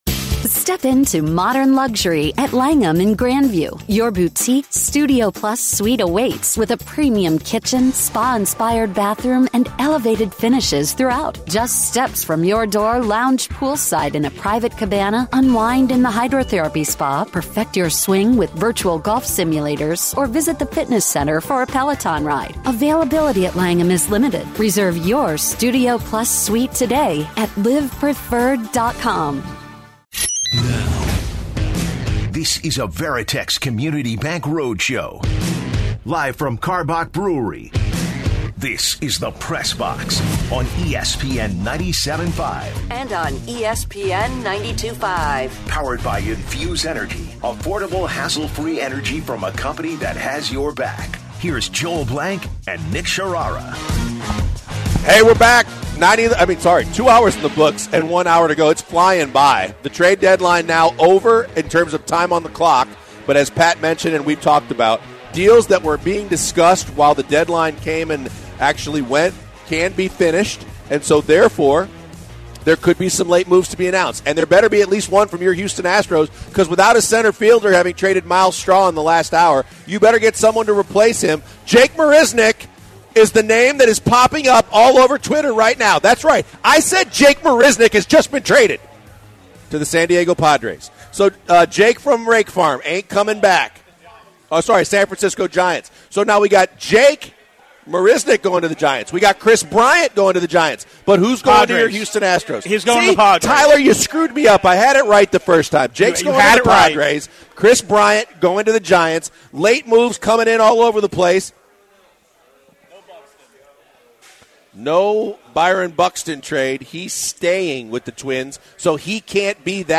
from Karbach Brewing for our ESPN 97.5 Summer Circuit